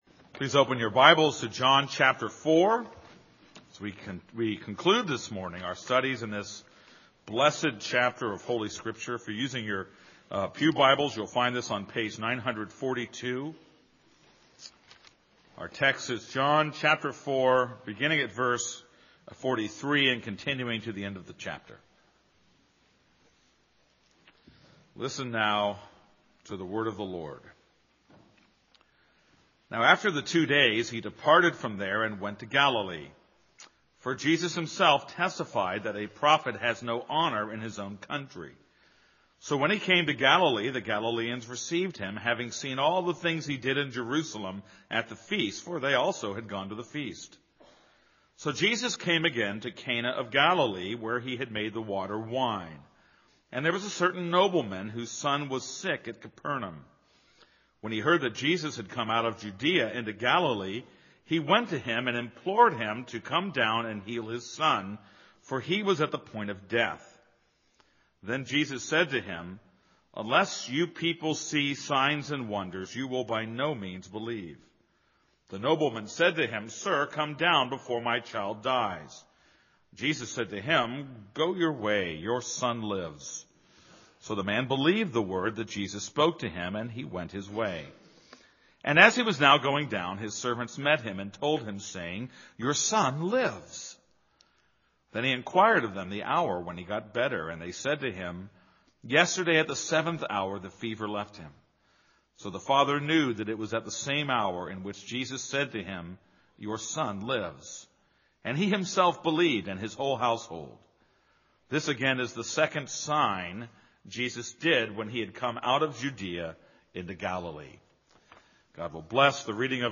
This is a sermon on John 4:43-54.